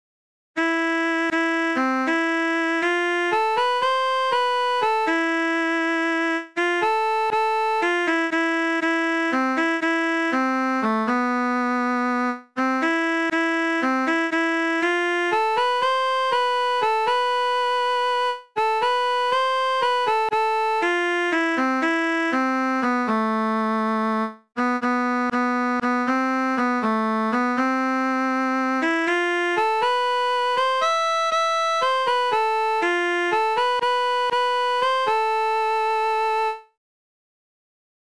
ﾁｪﾛ